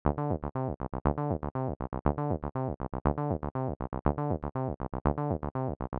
1-3_Basic_Sequence_TB-303_Example.mp3